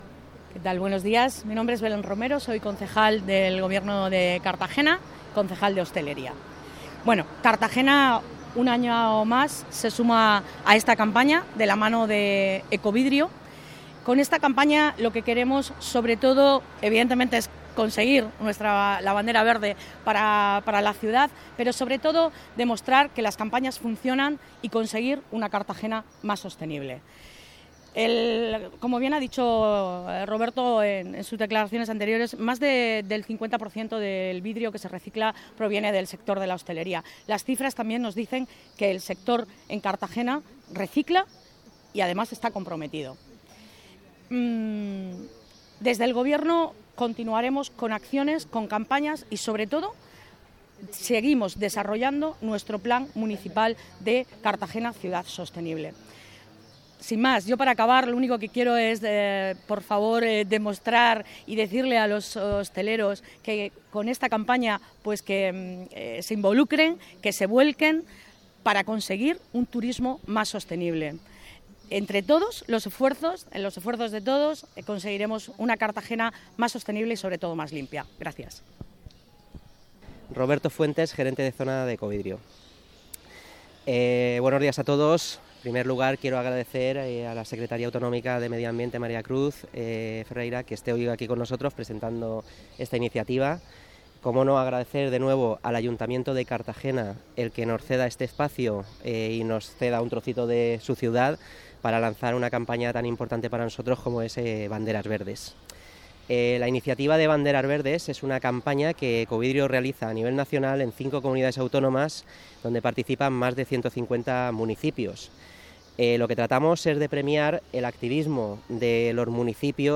Enlace a Declaraciones de Belén Romero